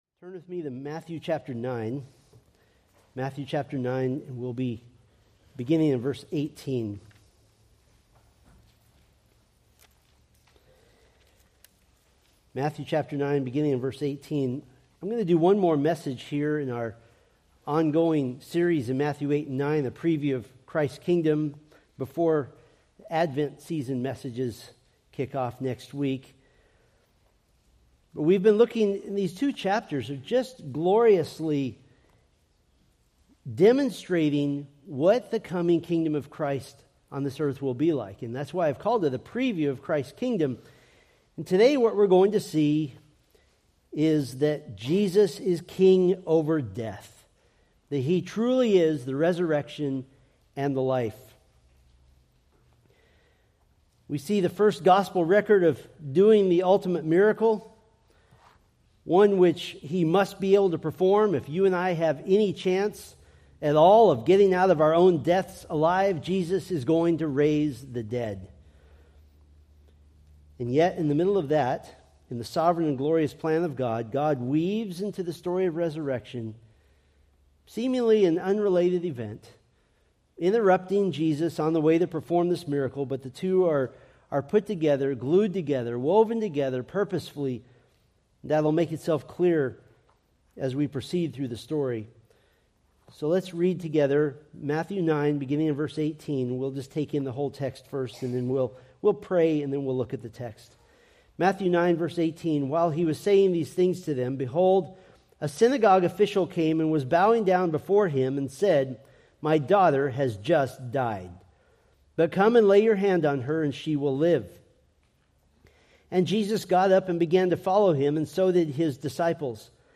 Preached December 1, 2024 from Matthew 9:18-26